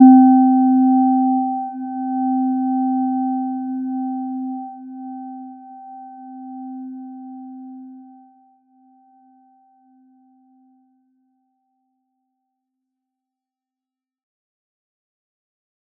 Gentle-Metallic-1-C4-mf.wav